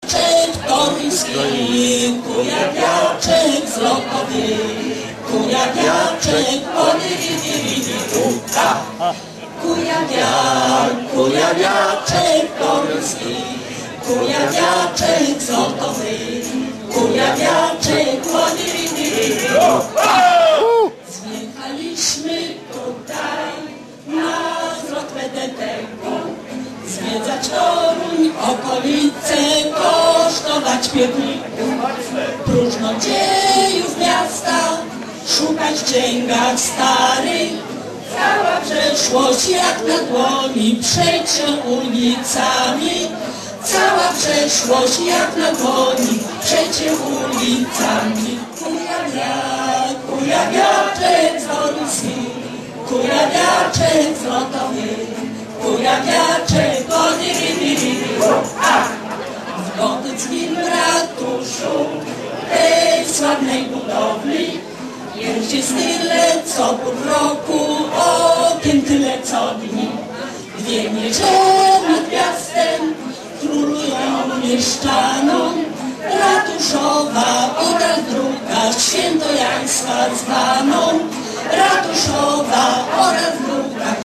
Poniżej odnośniki z fragmentami występu.